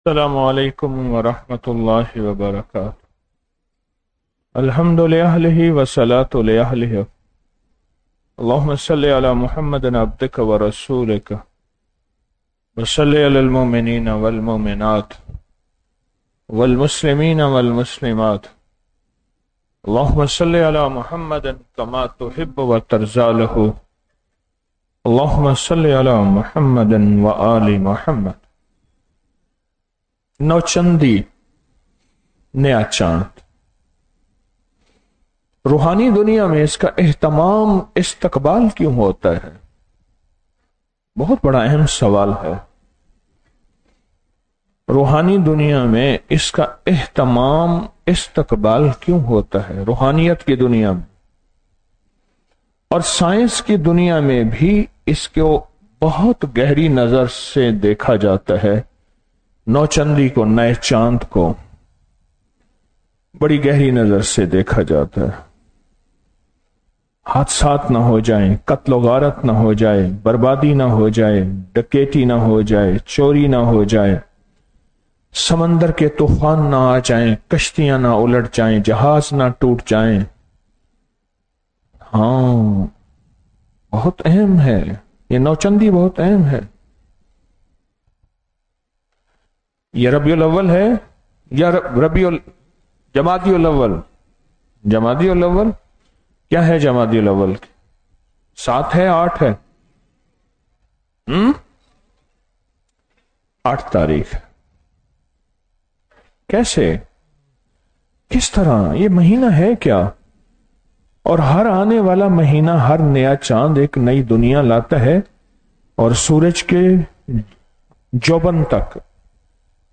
08 سوا کروڑ سورۃ الاخلاص | 30 اکتوبر 2025 | نو چندی شبِ جمعہ محفل